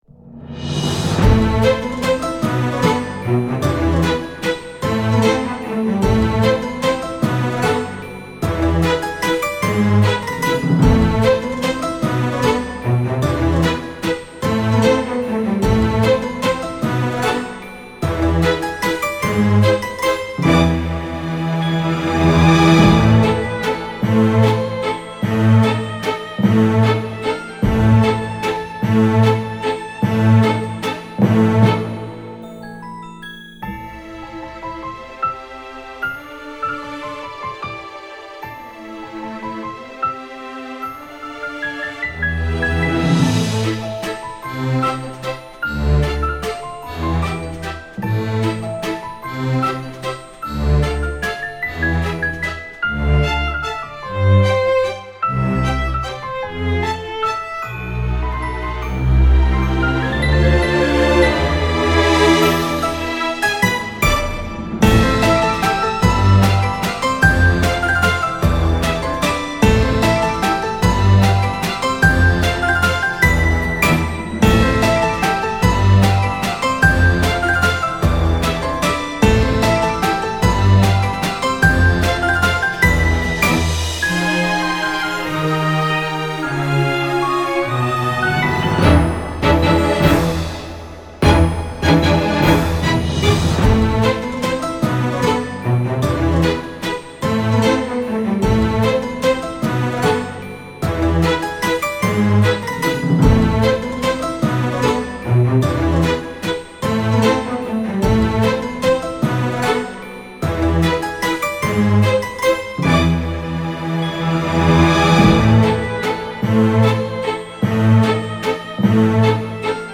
儚さと寂しさがありつつも、カッコ良い曲になりました。